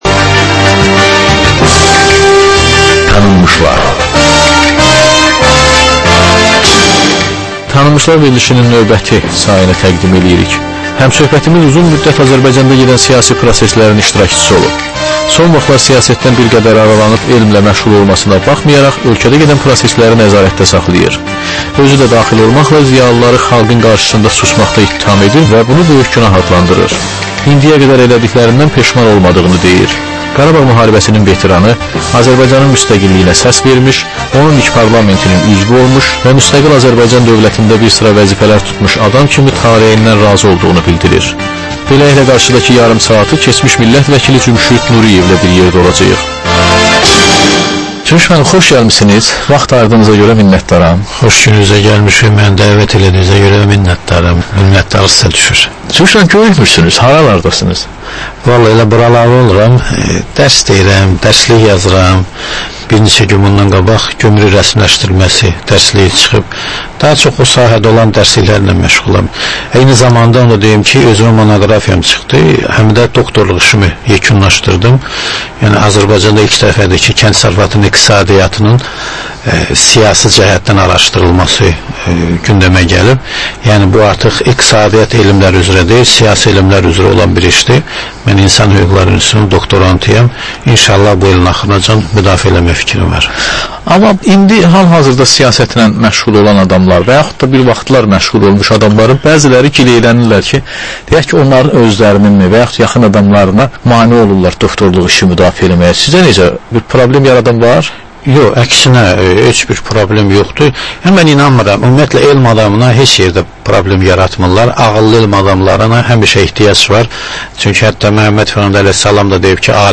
Dəyirmi masa söhbətinin təkrarı.